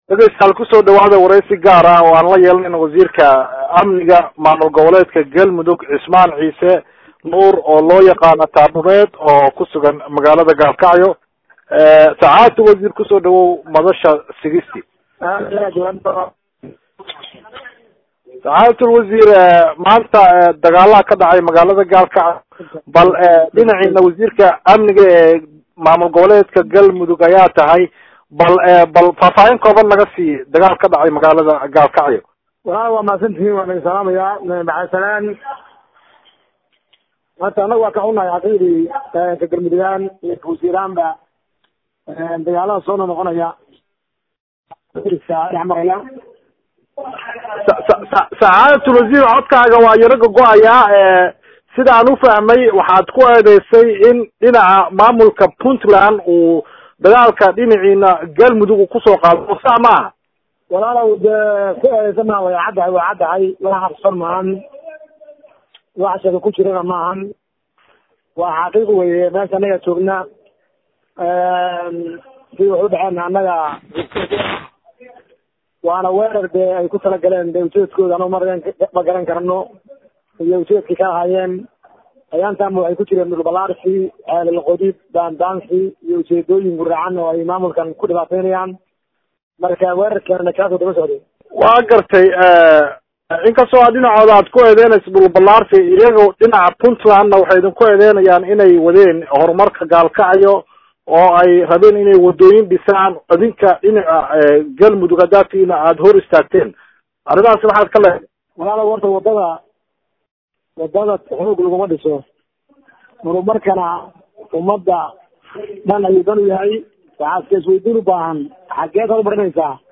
Waraysiga wasiirka amniga maamulka Galmudug Cismaan Ciise Nuur
Waraysiga-wasiirka-amniga-maamulka-Galmudug-Cismaan-Ciise-Nuur-.mp3